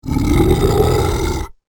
Scary Monster Growl Roar 6 Sound Effect Download: Instant Soundboard Button